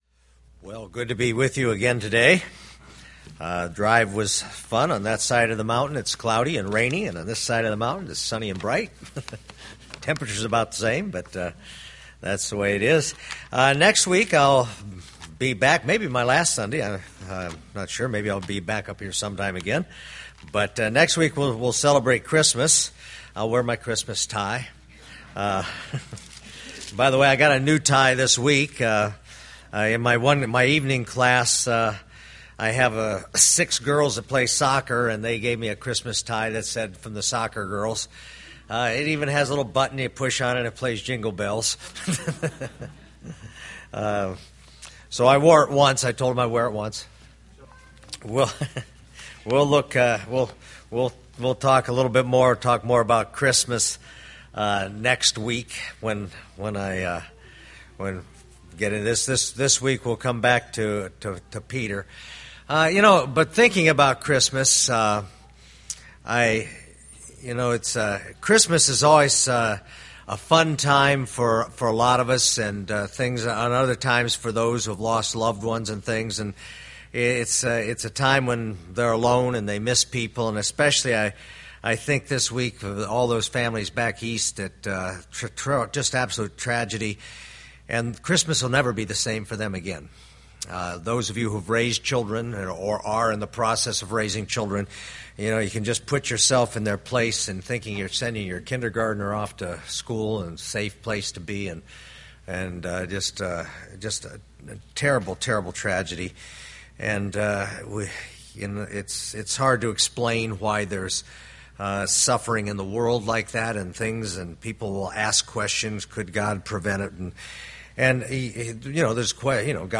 1 Peter Sermon Series